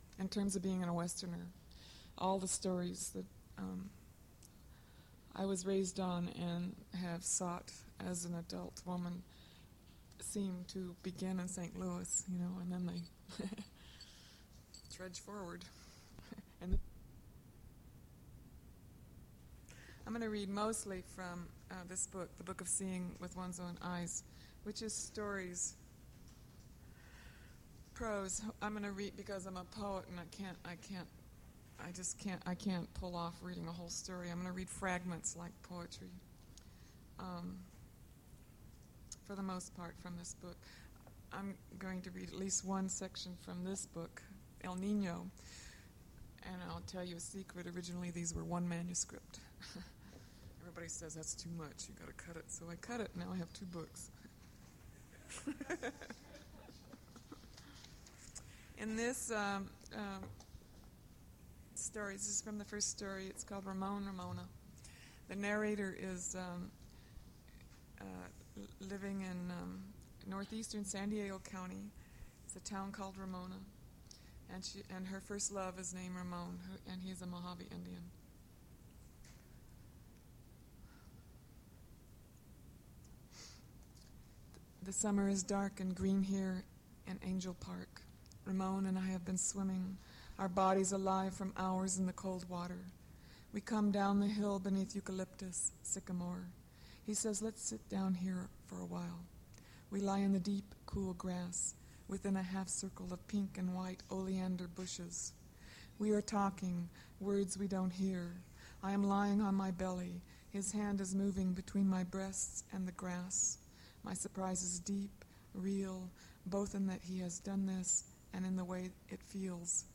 Poetry reading
mp3 edited access file was created from unedited access file which was sourced from preservation WAV file that was generated from original audio cassette.
1964-2014 Note Cut the music part during the first part.c There is a gap at 27:43 in the edited file and the gap is cut.